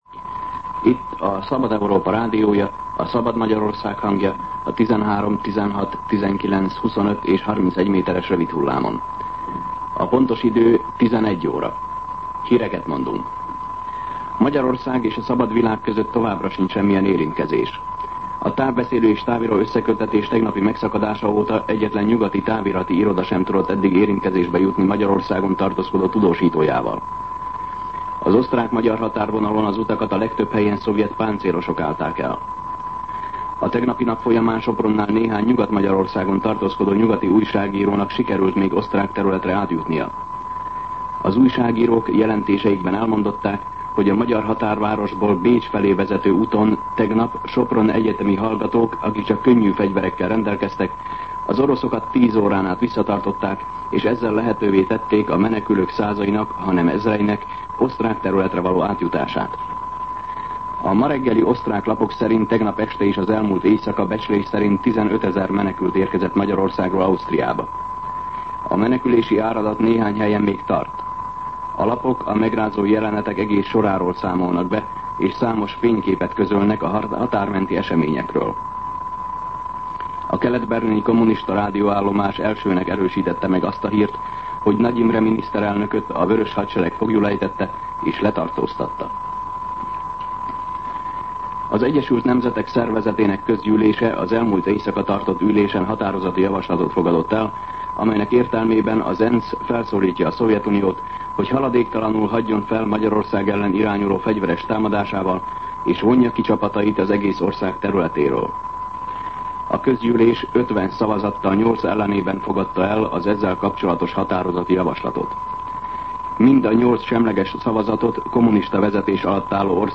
11:00 óra. Hírszolgálat